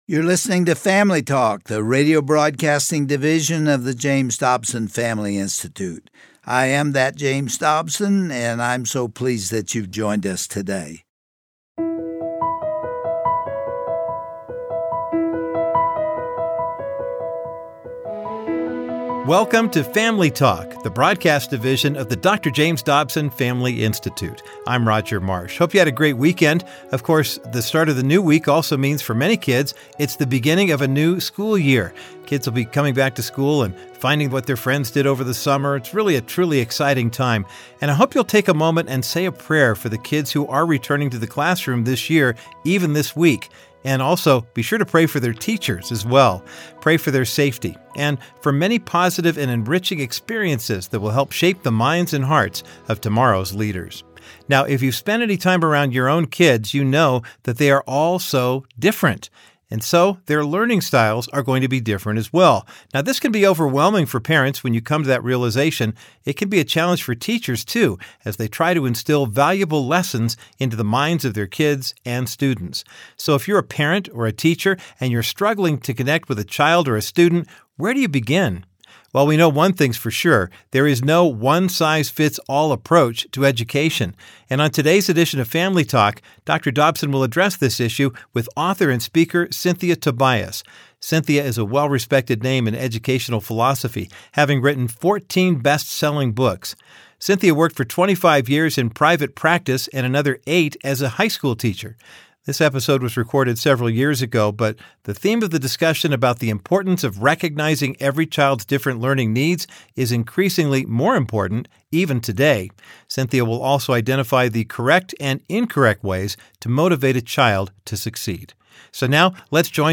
If you have a strong-willed child struggling in school, and your frustration levels are through the roof, you may want to turn up the volume and lean into today’s classic edition of Family Talk.